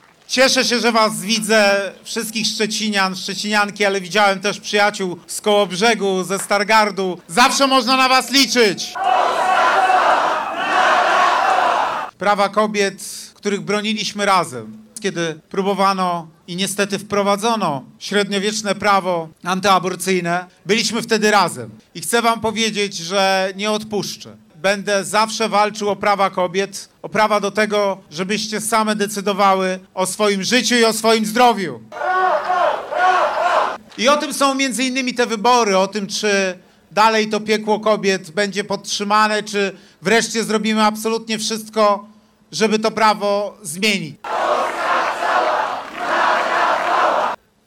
Plac Solidarności w Szczecinie wypełnił się w czwartkowy wieczór zwolennikami Rafała Trzaskowskiego, kandydata Koalicji Obywatelskiej na prezydenta.
SZCZ-Trzaskowski-Wiec.mp3